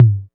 808 TOM 01.wav